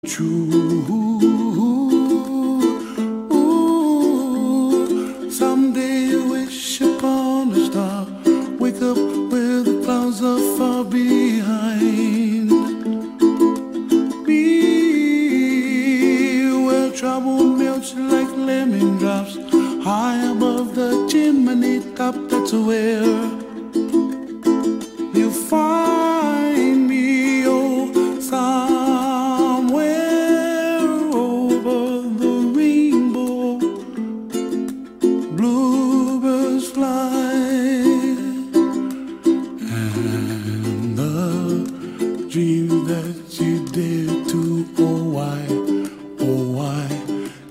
Classique